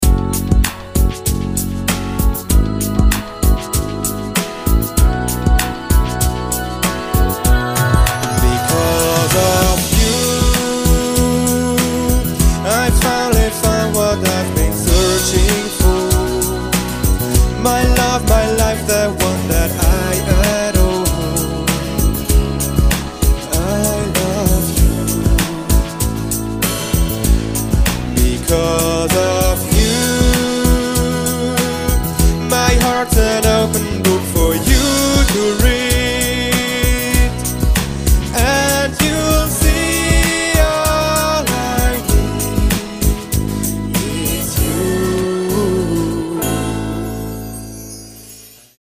Der I - V - VI - IV - Mainstream - Popsong - Contest